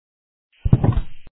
1 channel
5_fall.mp3